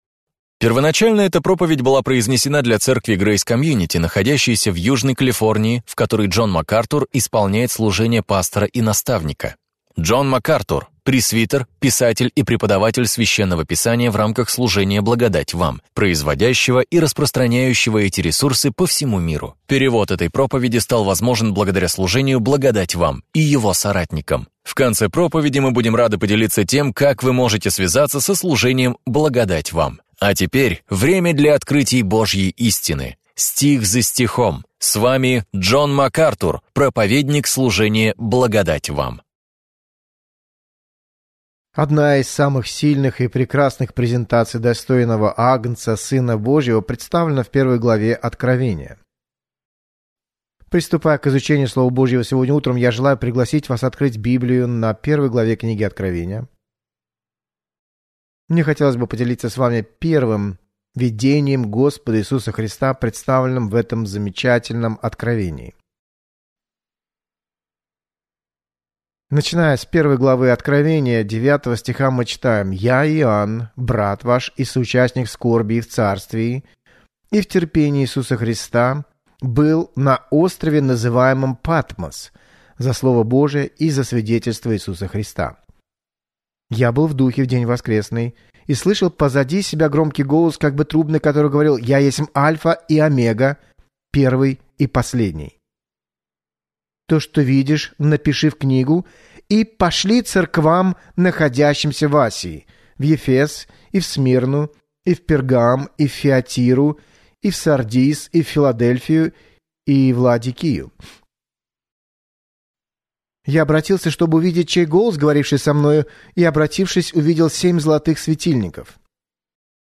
«Анатомия Церкви» – это ценная проповедь, поясняющая, как вы и ваша церковь можете прославлять Бога!